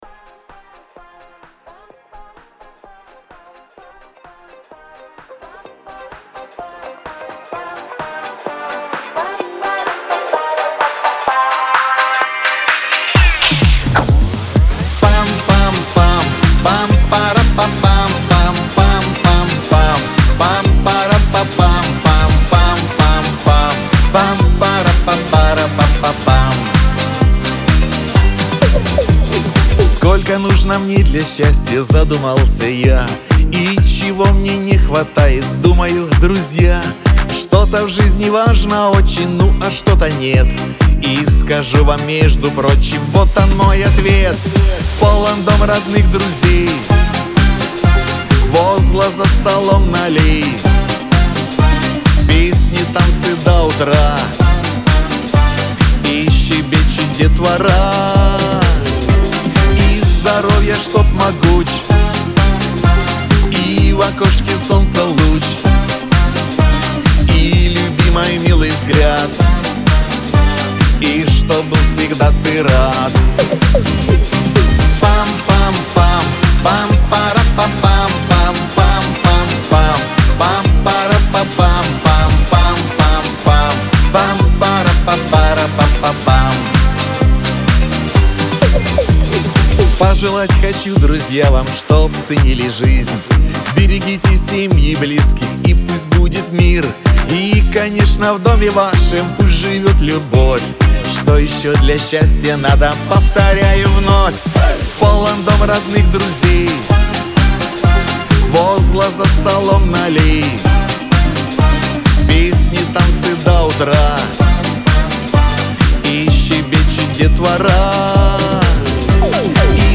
Лирика
диско